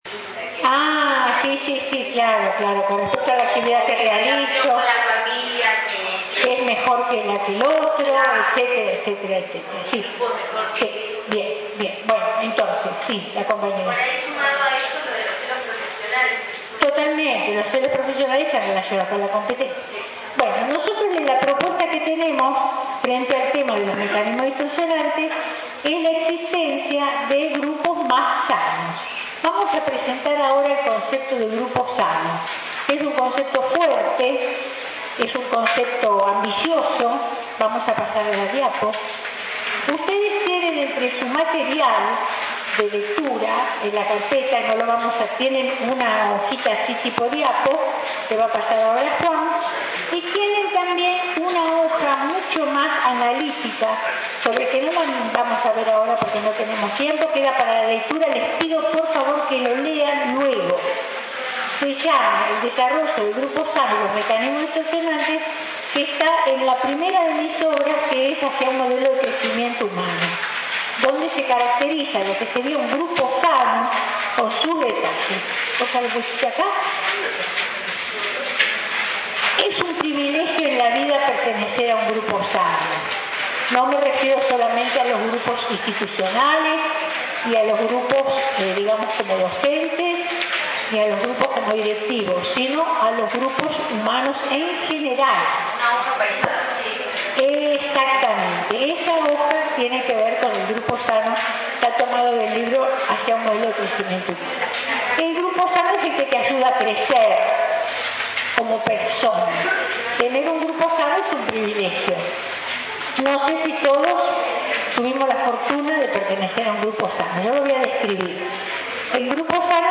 Jornadas de formación para Concurso de Supervisores/as